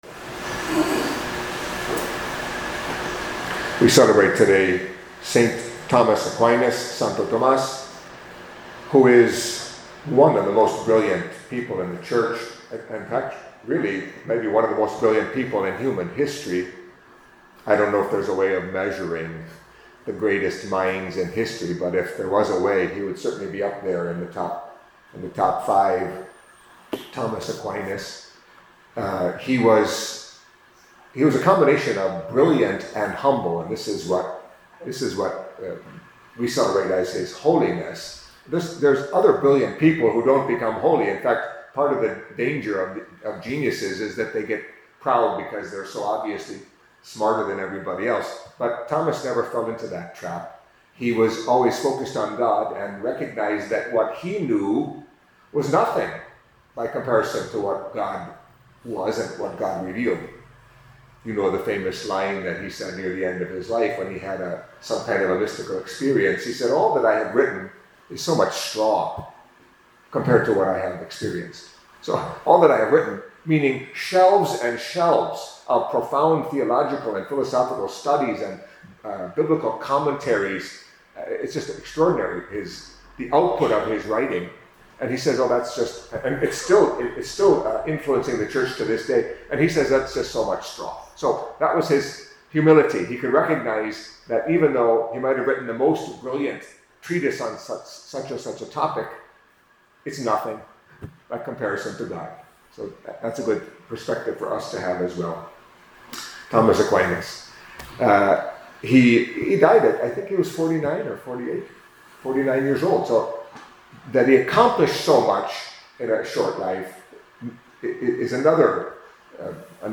Catholic Mass homily for Tuesday of the Third Week in Ordinary Time